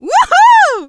shelly_kill_04.wav